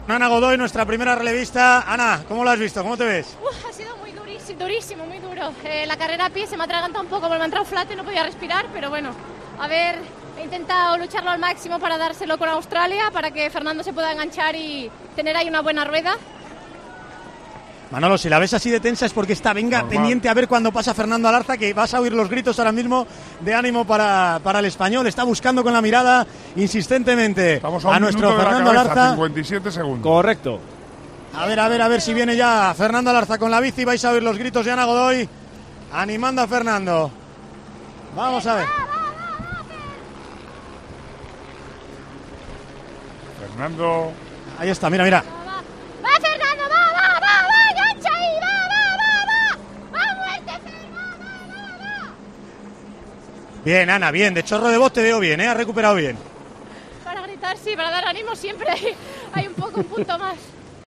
La arenga de Anna Godoy, en El Partidazo de COPE, a sus compañeros en la prueba de triatlón mixto